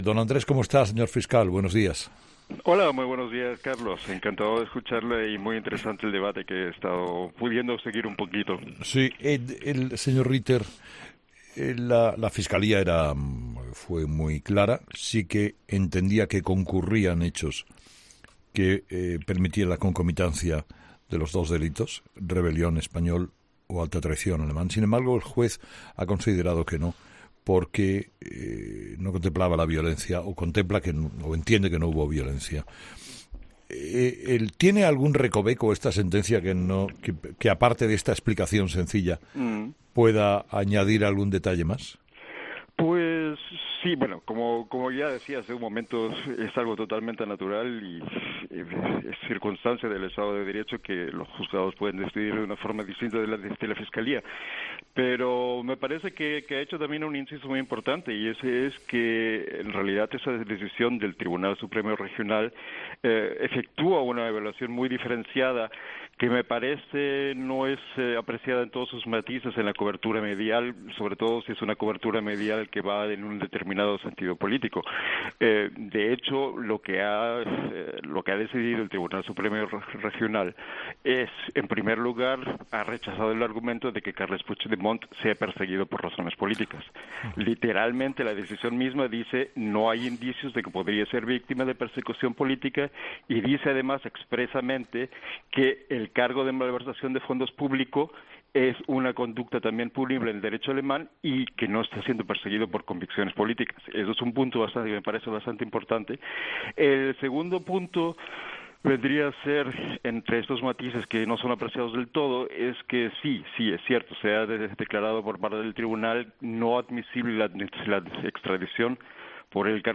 Andrés Ritter, fiscal jefe de Rostock, matiza en 'Herrera en COPE' que "el tribunal declara no admisible la extradición de Puigdemont por cargo de rebelión, pero no porque no haya existido violencia."